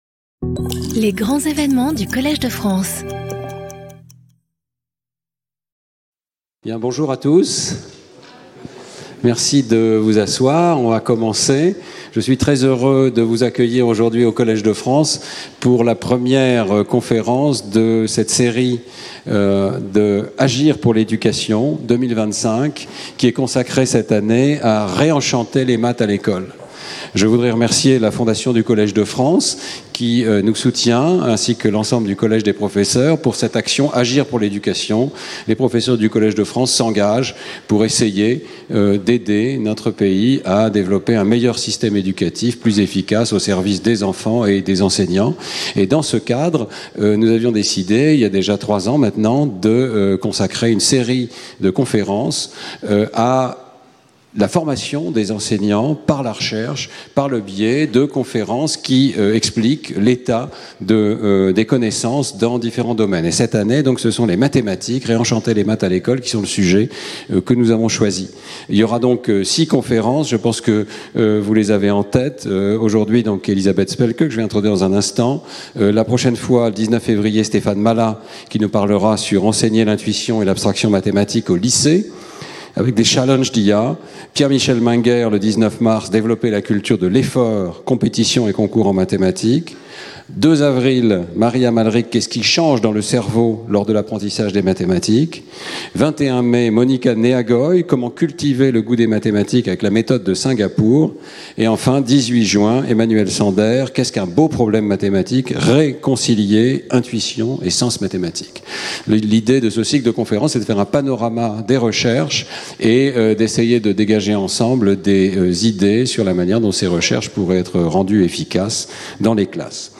Speaker(s) Elizabeth Spelke Harvard Professor of Psychology